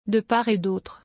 Prononciation et aspects linguistiques
La transcription phonétique internationale s’écrit : [də paʁ e dotʁ].
L’accent tonique porte naturellement sur la syllabe finale “autre”.